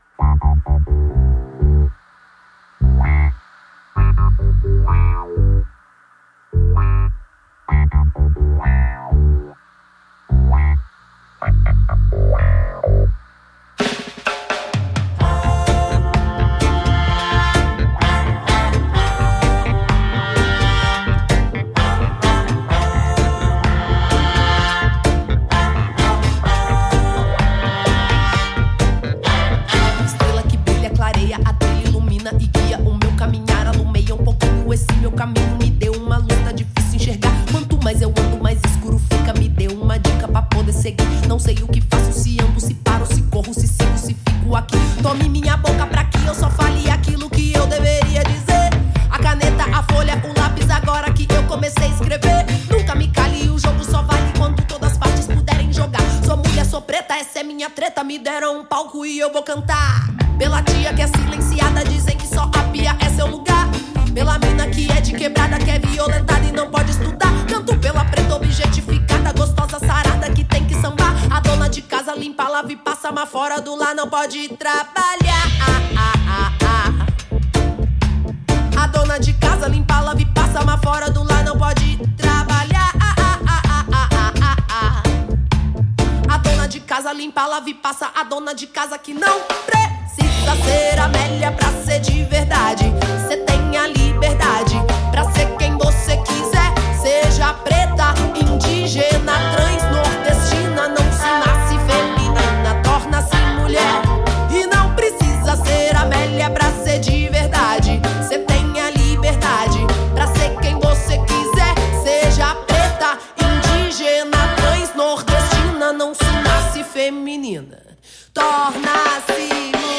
al Rototom Sunsplash 2024!
Artista-a-la-Vista-Intervista-Bia-Ferreira-Rototom-2024.mp3